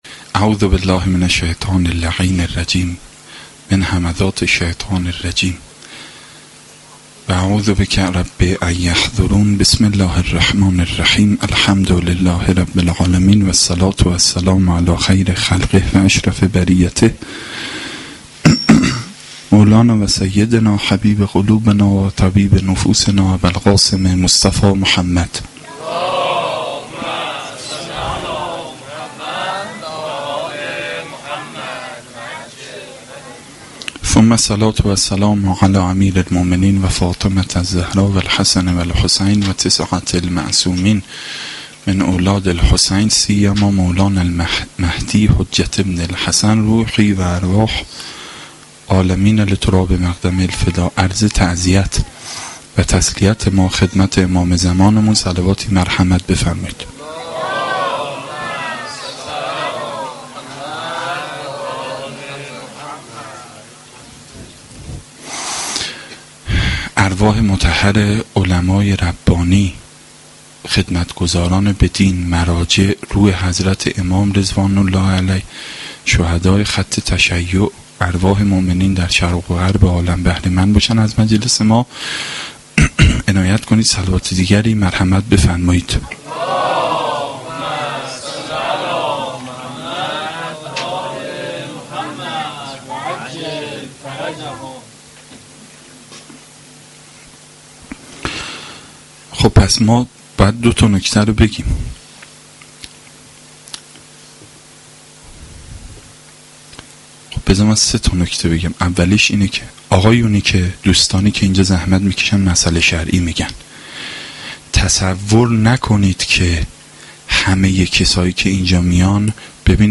سخنرانی
Shab-6-Moharam-1.mp3